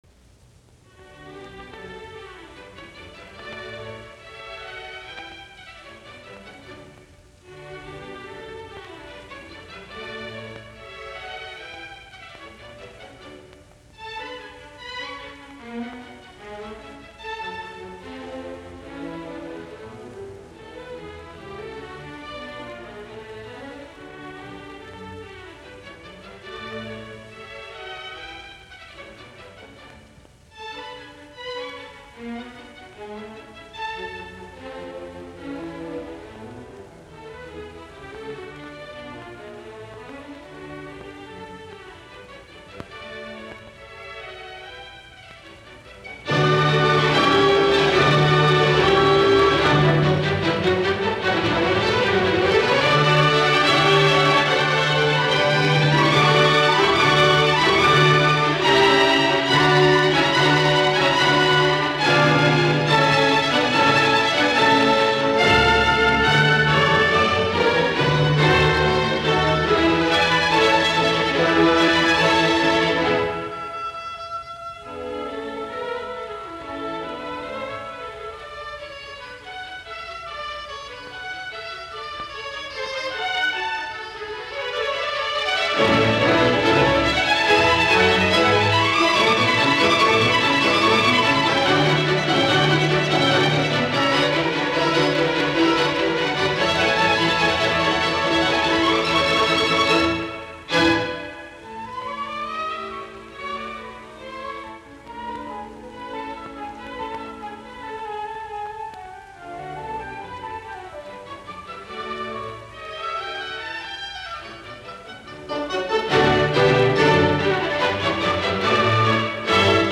Sinfoniat
D-duuri
Andante